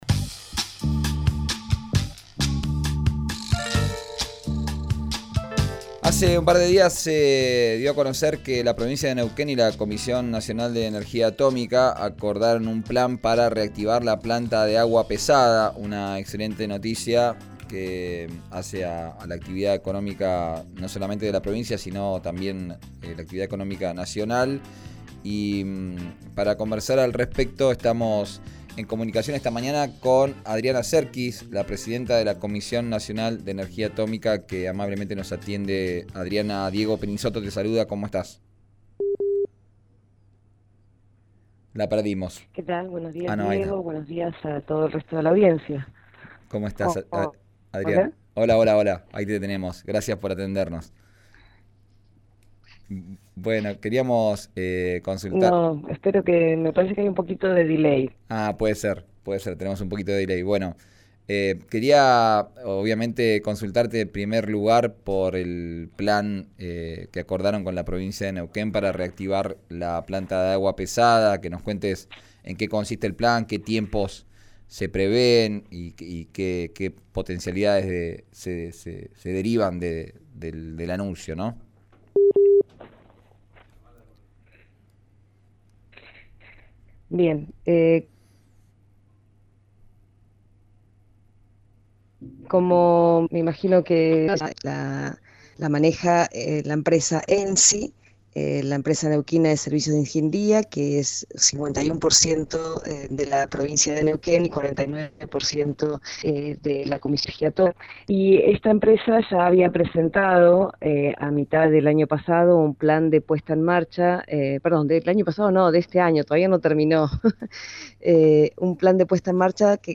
La titular de la cartera, Adriana Serquis, habló con RÍO NEGRO RADIO.